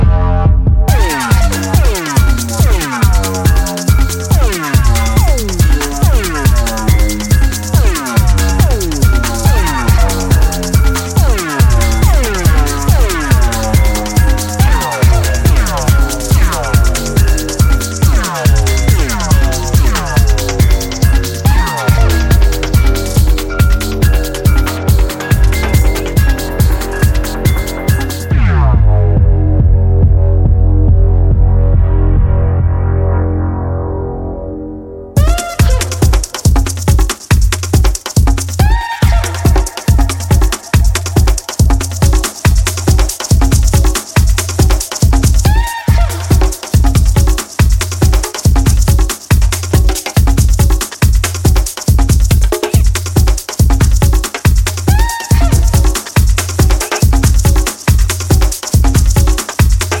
140で刻まれる強固なキックとカラフルなラテンパーカッション